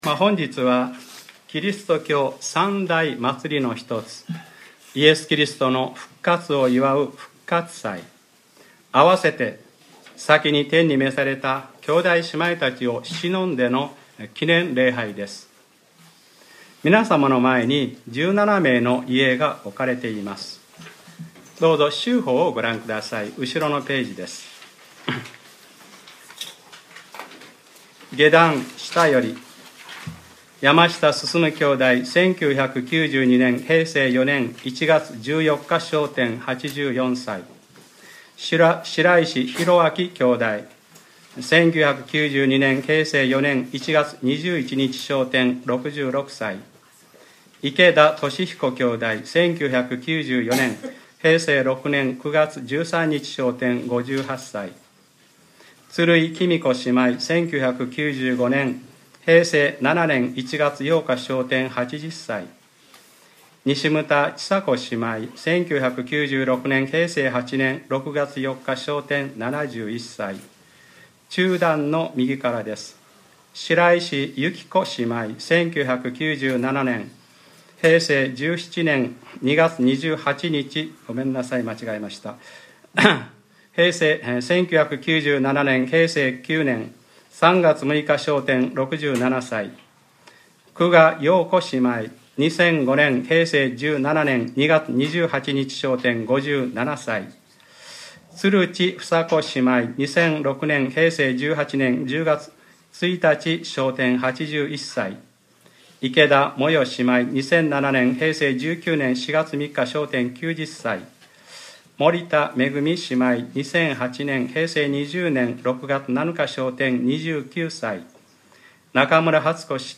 2014年 4月20日（日）礼拝説教『私たちをもよみがえらせて下さいます』